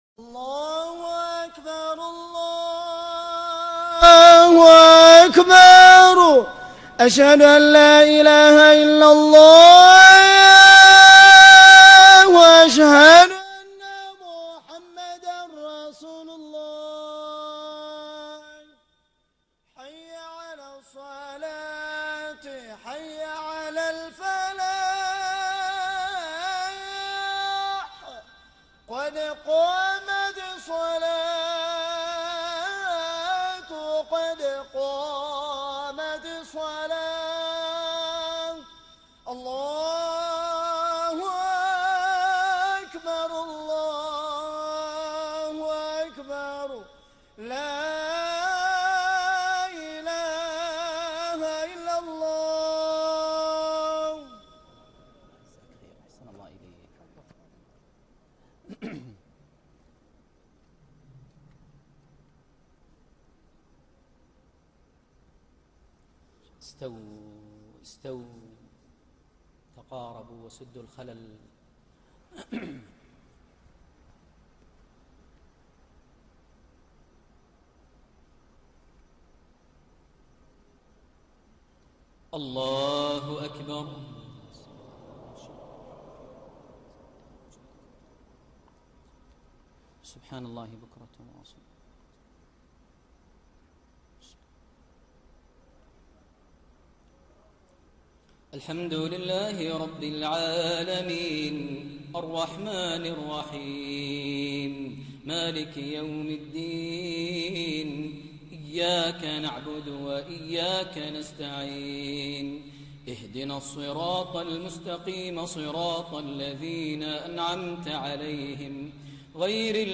من السودان | صلاة الجمعة ١٤٣٣هـ لسورتي الأعلى - الغاشية > زيارة الشيخ ماهر المعيقلي لدولة السودان عام ١٤٣٣هـ > المزيد - تلاوات ماهر المعيقلي